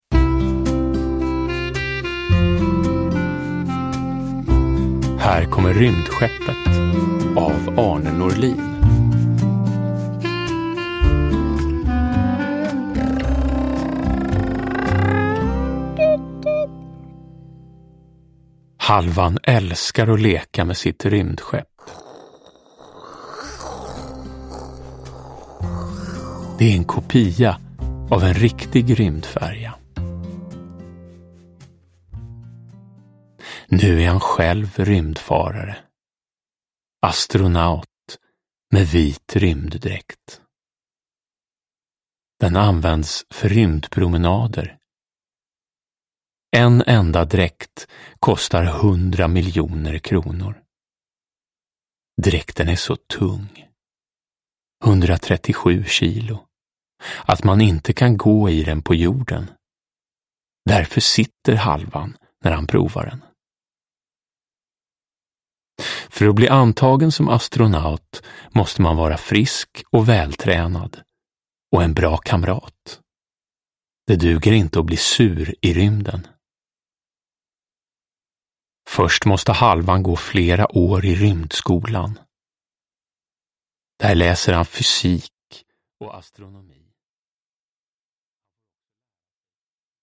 Uppläsare: Jonas Karlsson